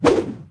footswing3.wav